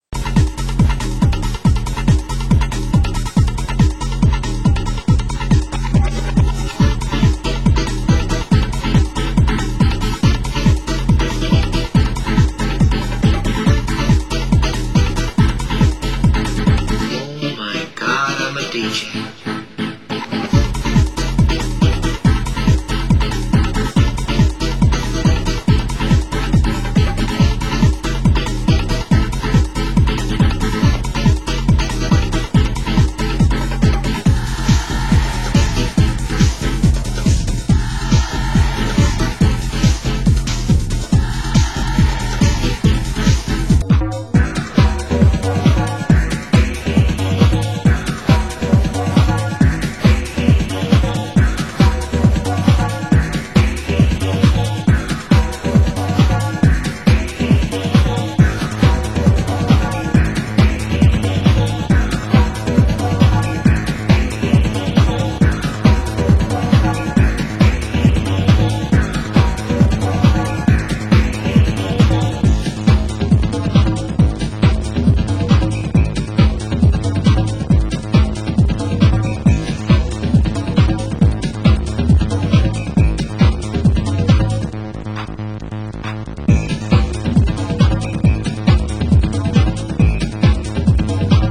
Format: Vinyl 12 Inch
Genre: Hardcore